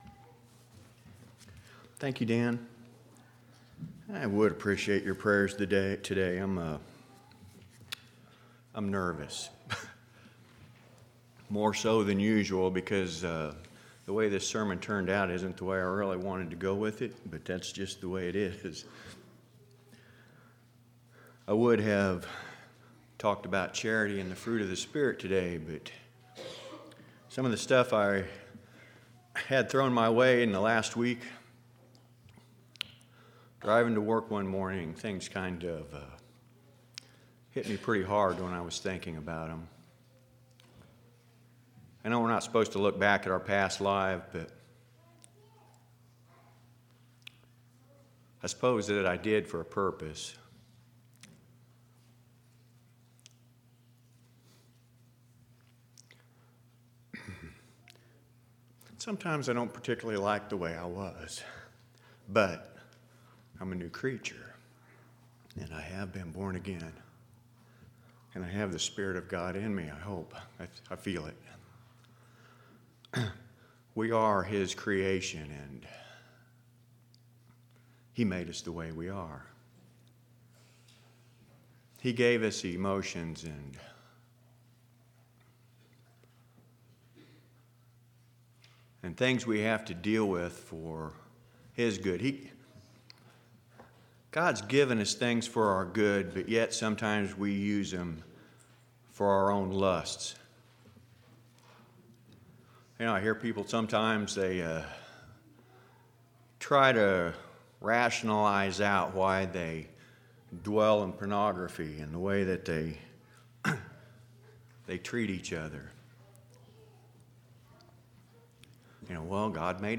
1/22/2012 Location: Temple Lot Local Event